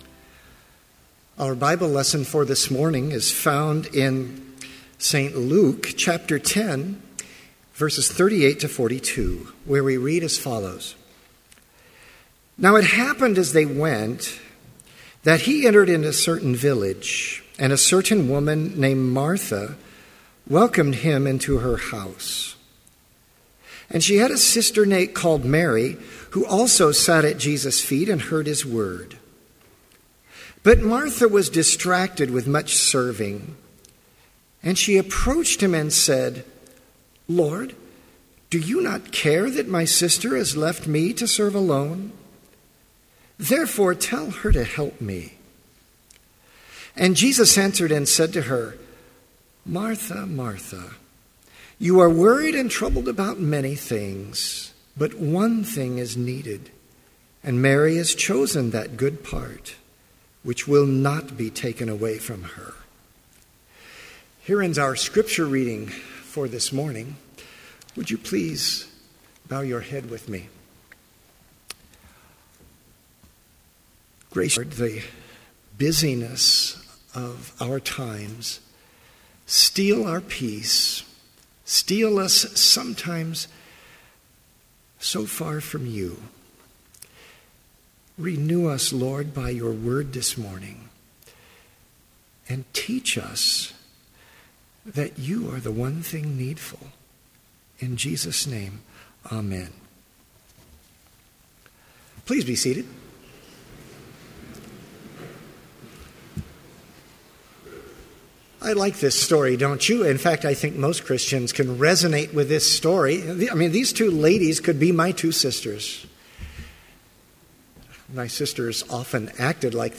Complete Service
• Hymn 182, vv. 1, 2 & 5, One Thing Needful
• Homily
This Chapel Service was held in Trinity Chapel at Bethany Lutheran College on Wednesday, January 14, 2015, at 10 a.m. Page and hymn numbers are from the Evangelical Lutheran Hymnary.